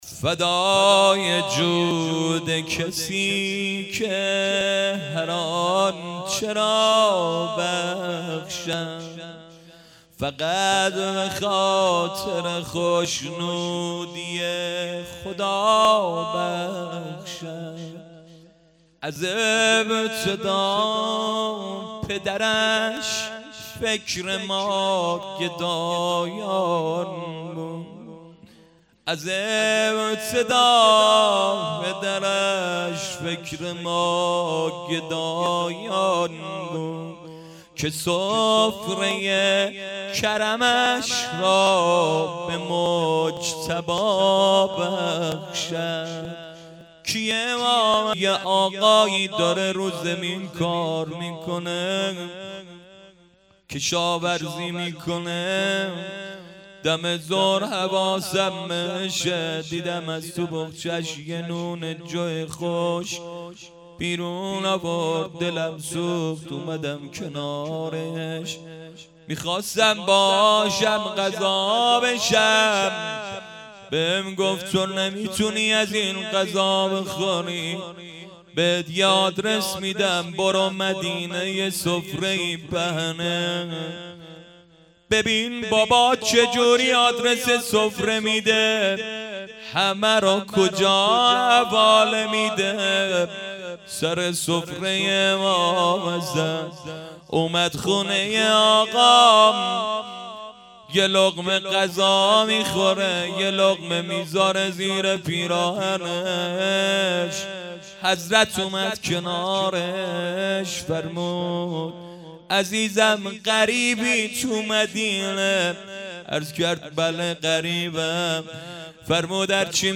فدای جود کسی که - روضه
rozeh.fadaye-jood-kasi-ke.mp3